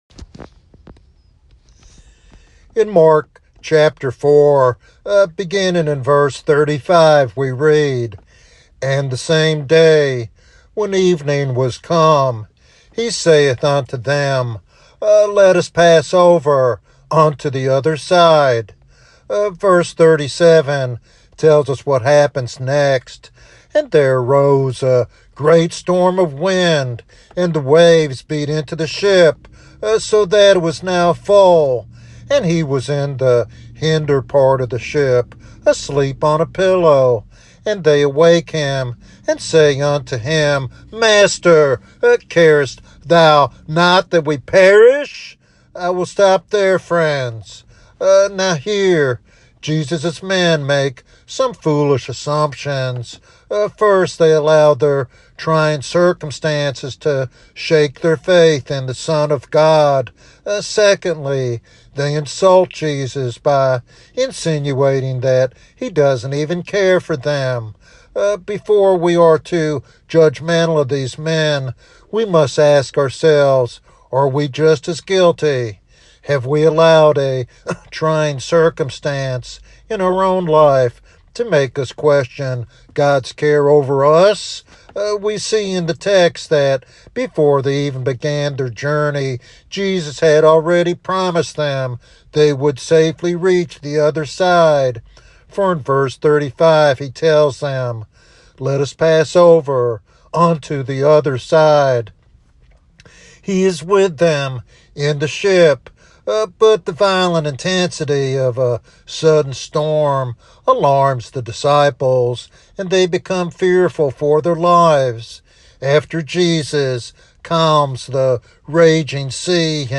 In this devotional sermon
Sermon Outline